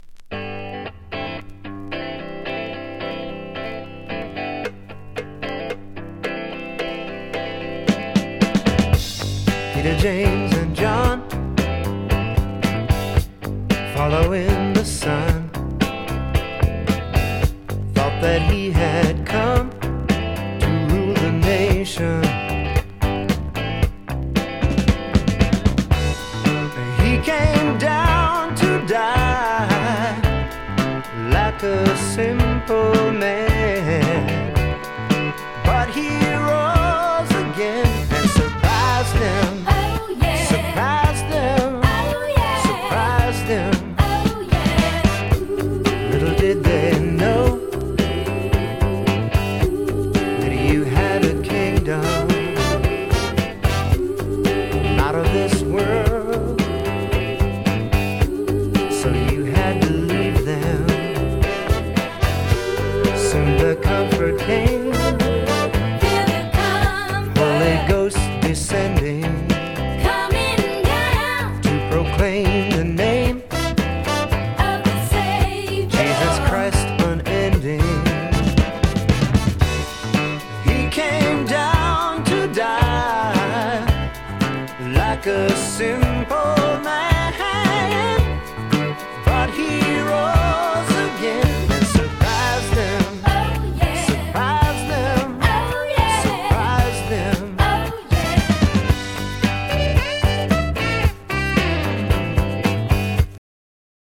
フォークSSWもので男女のボーカル。
いいメロディで凝った作りのメロディにシンプルなアレンジはフォークファンには嬉しい作りですね。